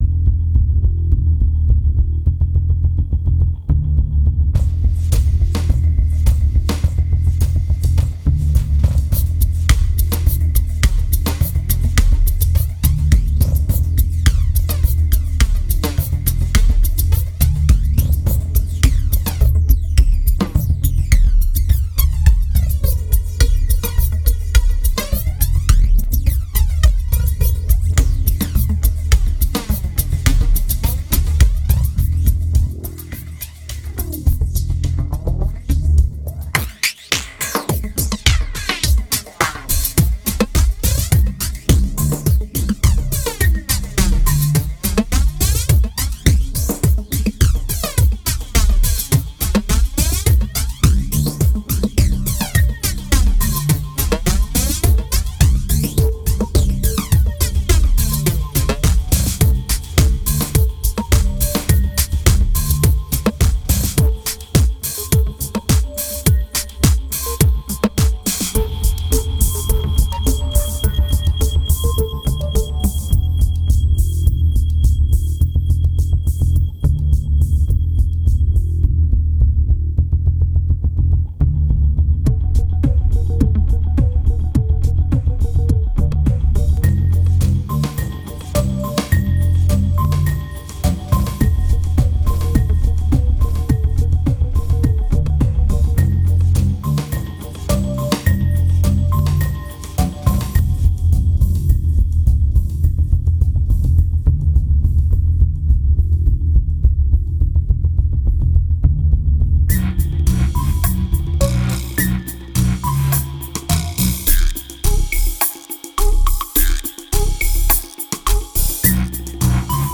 2190📈 - -17%🤔 - 105BPM🔊 - 2009-09-10📅 - -233🌟